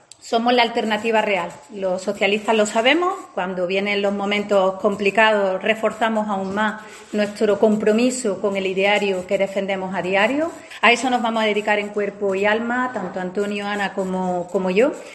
Isabel Ambrosio, cabeza de lista por Córdoba del PSOE